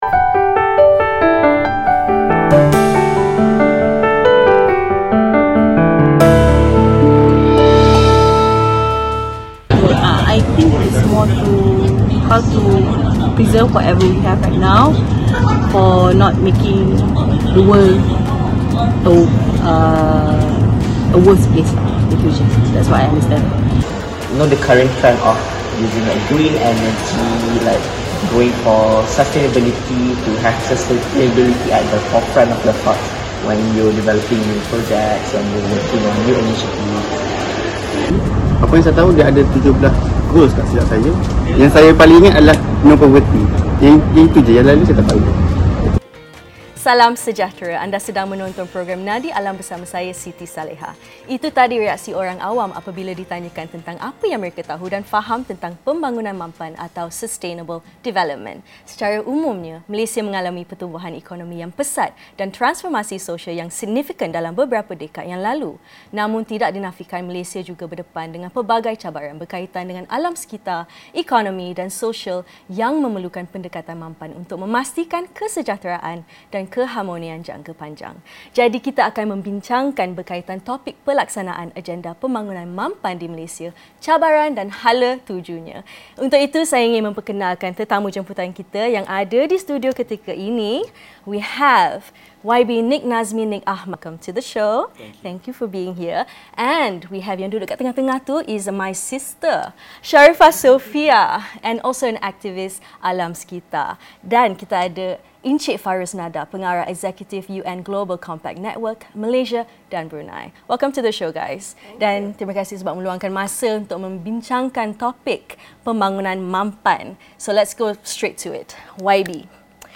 Nadi Alam: Sustainable Development Roundtable